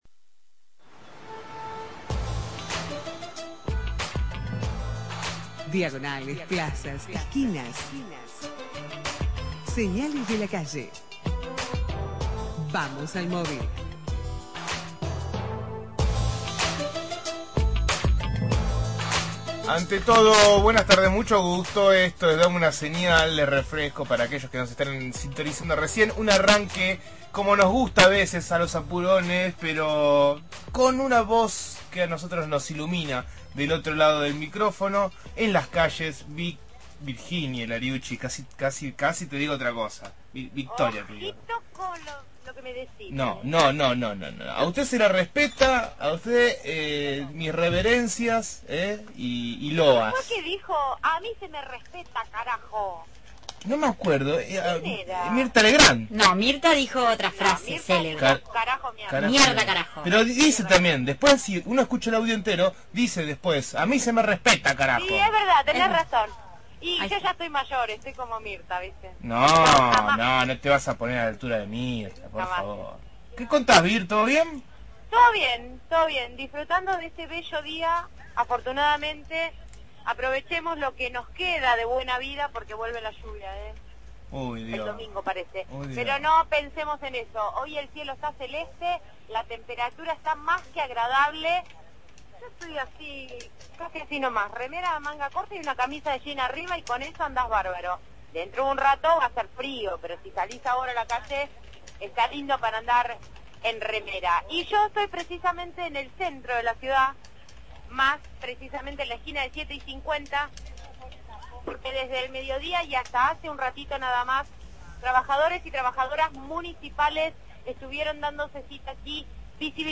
MOVIL/ Reclamo de trabajadores despedidos de la Municipalidad – Radio Universidad